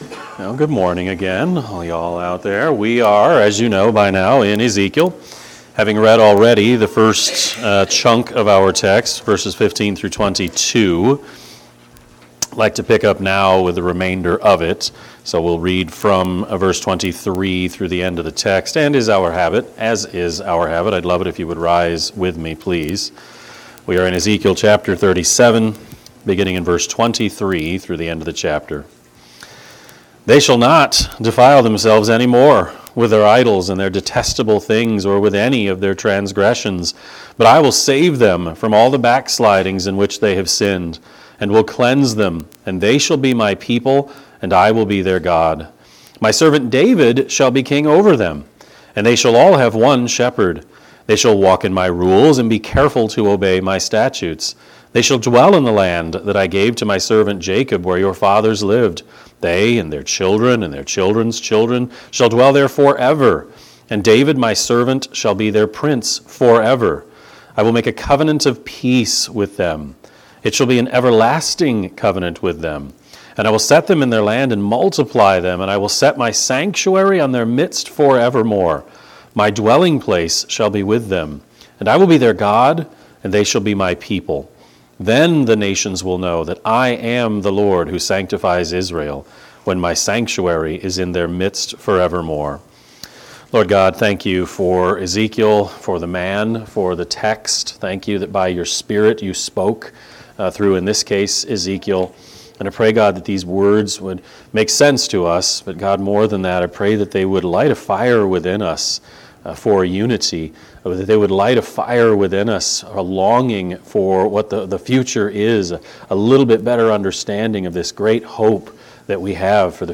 Sermon-3-9-25-Edit.mp3